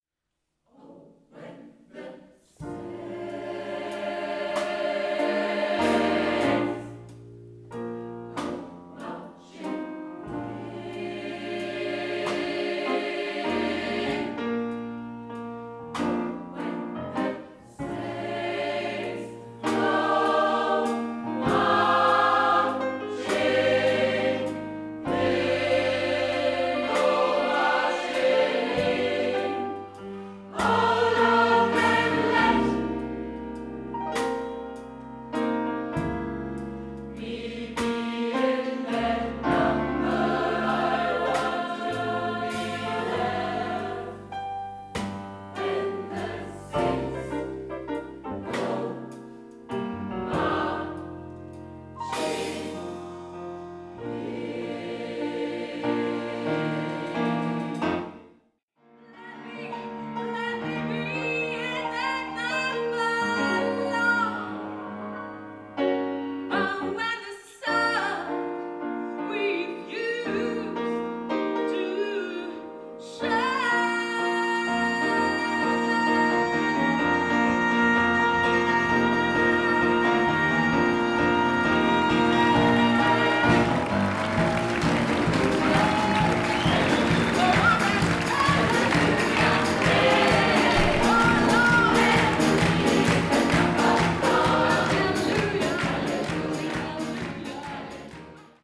Demo aus Band 4 (SAM+Klavier):
• SAB + Piano